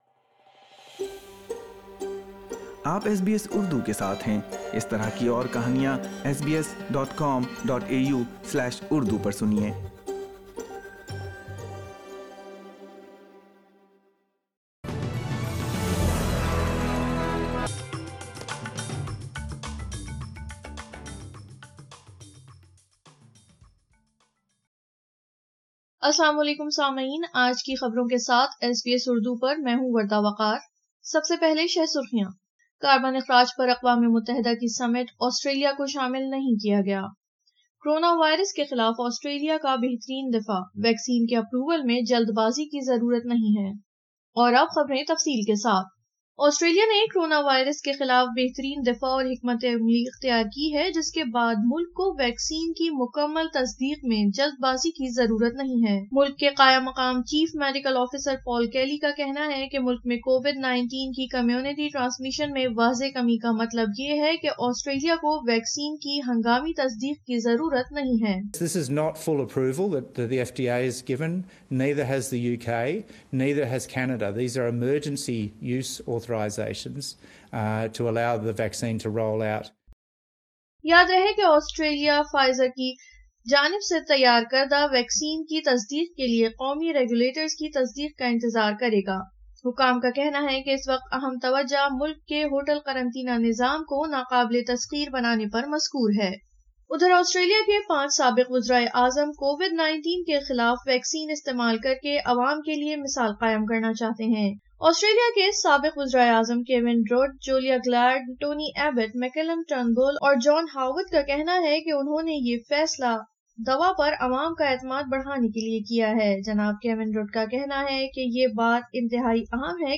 اردو خبریں 13 دسمبر 2020